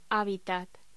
Locución: Habitat
voz